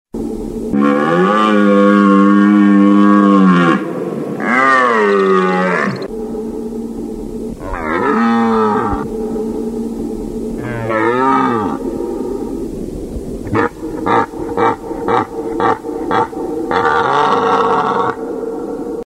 На этой странице собраны натуральные звуки оленей: от нежного фырканья до мощного рева в брачный период.
Угрожающий рев